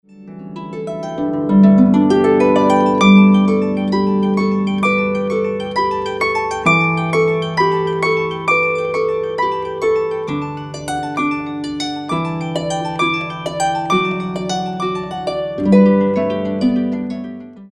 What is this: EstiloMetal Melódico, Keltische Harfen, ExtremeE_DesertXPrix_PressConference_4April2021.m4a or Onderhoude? Keltische Harfen